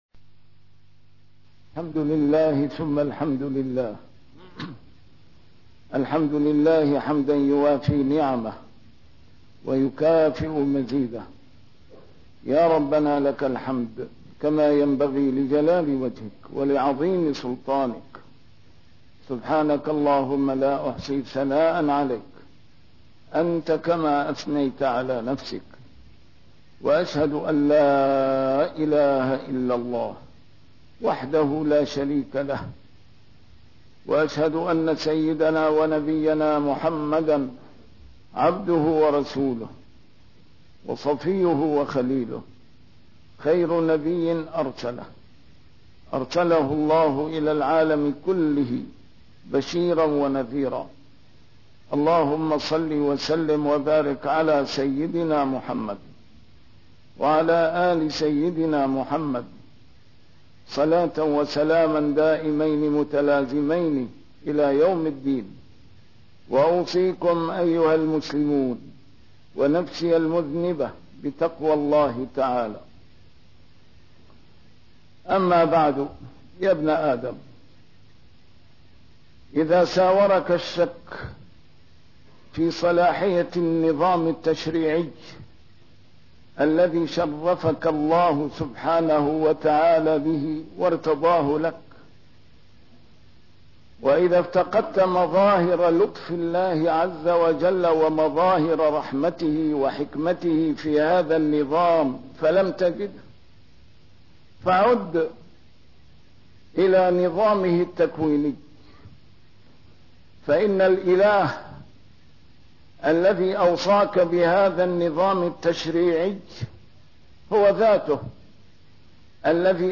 A MARTYR SCHOLAR: IMAM MUHAMMAD SAEED RAMADAN AL-BOUTI - الخطب - ظَهَرَ الْفَسادُ فِي الْبَرِّ وَالْبَحْرِ بِما كَسَبَتْ أَيْدِي النّاسِ